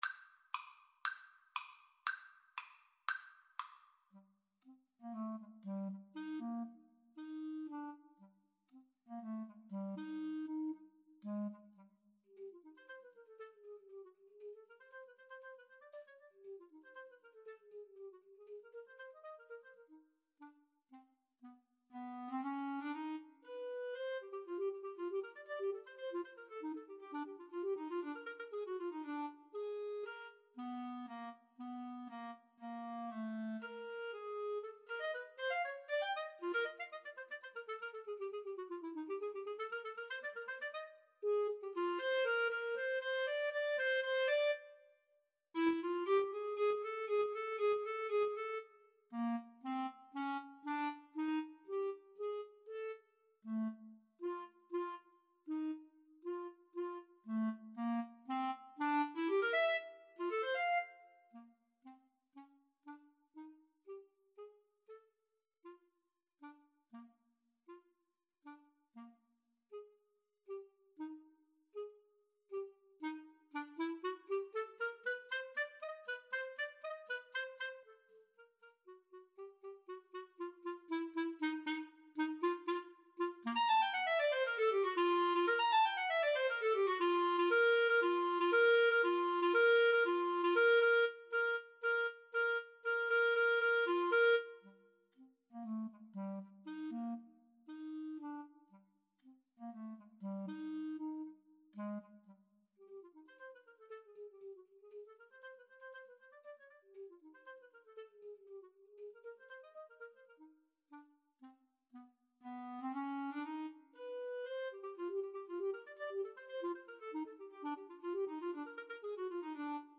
Eb major (Sounding Pitch) F major (Clarinet in Bb) (View more Eb major Music for Clarinet Duet )
Allegro giusto = 118 (View more music marked Allegro)
Classical (View more Classical Clarinet Duet Music)